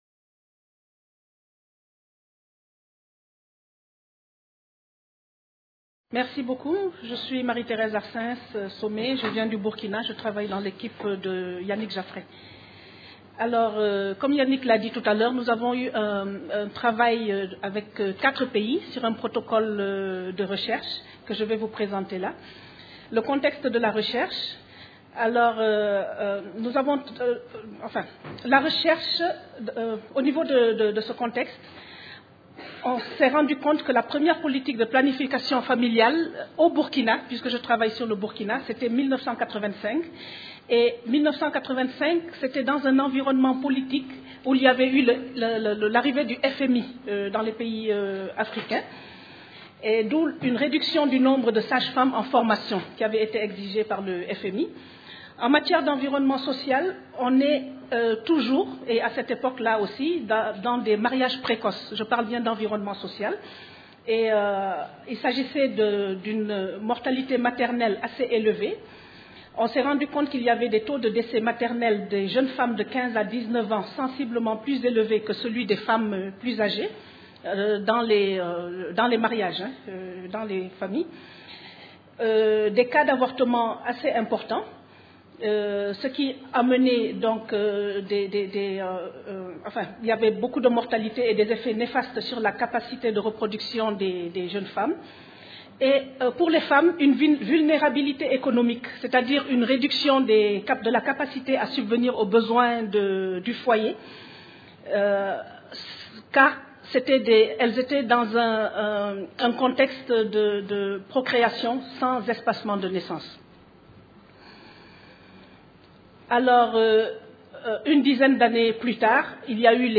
La construction sociale de la vie génésique et sa durée au Burkina Faso. Conférence enregistrée dans le cadre du Colloque International Interdisciplinaire : Droit et Santé en Afrique.